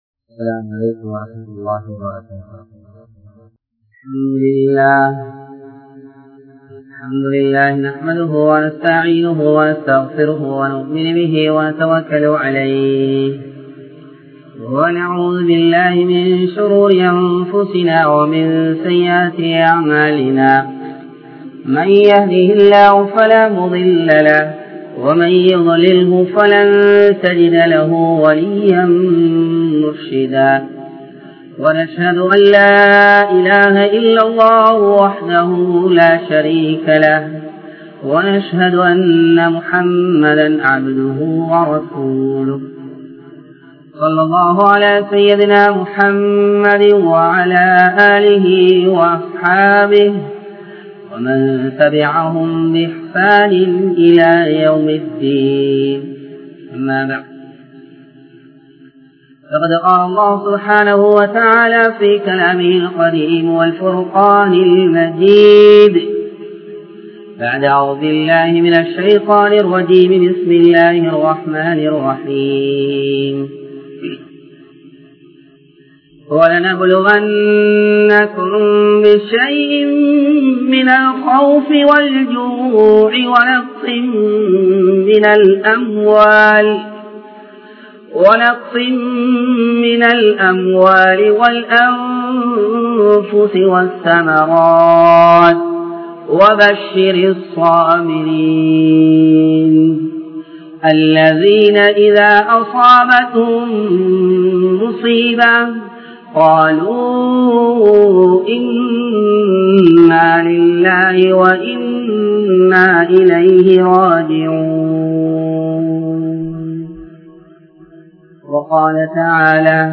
Suvarkatthin Vilai Enna?(சுவர்க்கத்தின் விலை என்ன?) | Audio Bayans | All Ceylon Muslim Youth Community | Addalaichenai
Puttalam, Maduranguli sameeragama Jumua Masjith